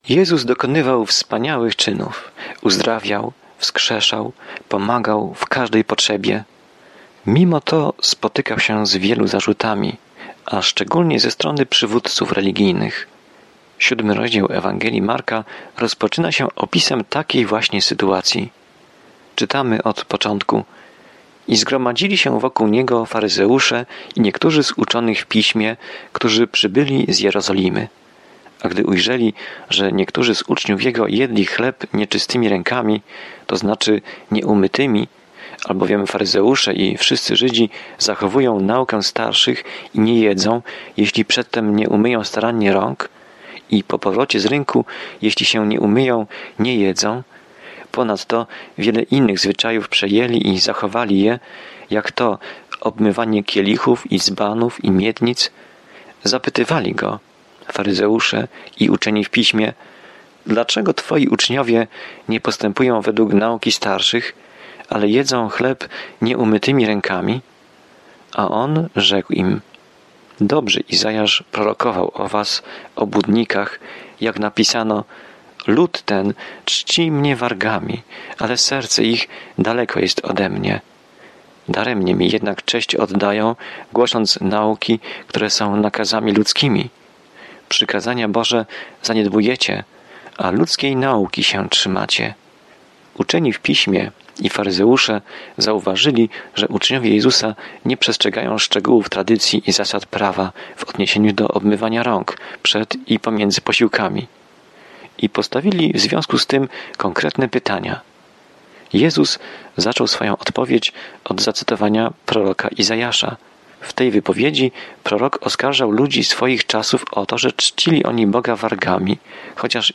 Pismo Święte Marka 7 Dzień 7 Rozpocznij ten plan Dzień 9 O tym planie Krótsza Ewangelia Marka opisuje ziemską służbę Jezusa Chrystusa jako cierpiącego Sługi i Syna Człowieczego. Codziennie podróżuj przez Marka, słuchając studium audio i czytając wybrane wersety ze słowa Bożego.